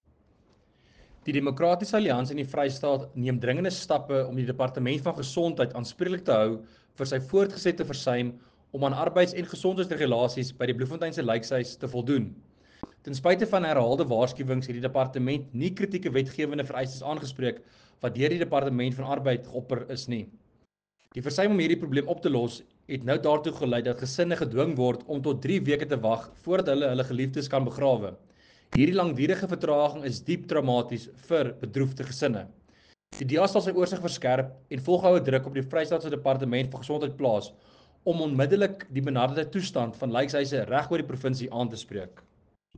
Afrikaans soundbite by Werner Pretorius MPL